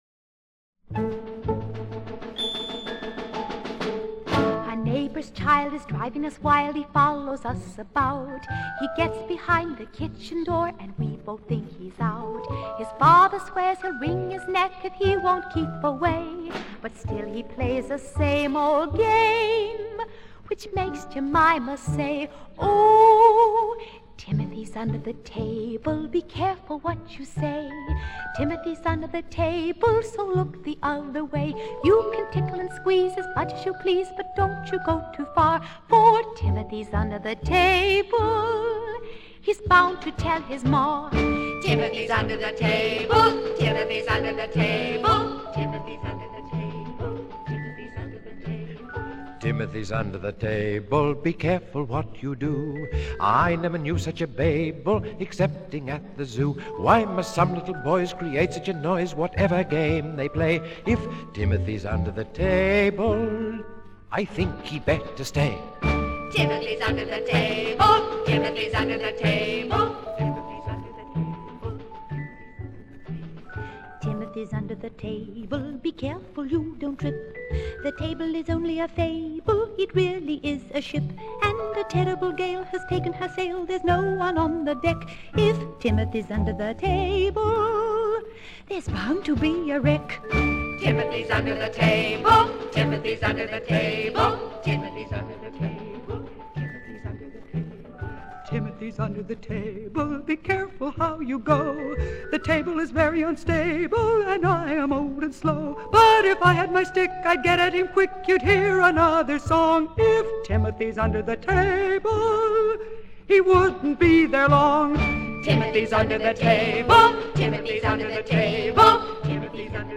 A musical play for children
with lots of simple and gay tunes